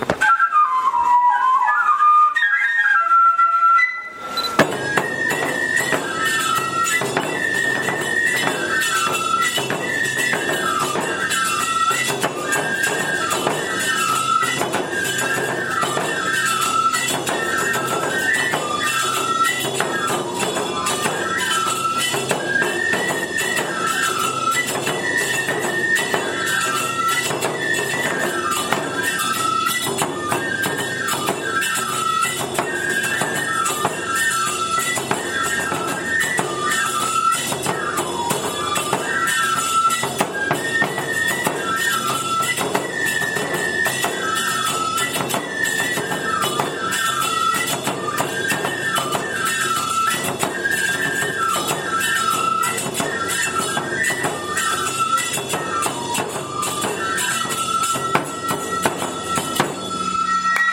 《 愛好会囃子部の演奏 》
2017(h29)年 ※電力ビル･グリーンプラザ(仙台市)
【デジタル録音】⇒ 進行ばやし
転ばしばやし　戻りばやし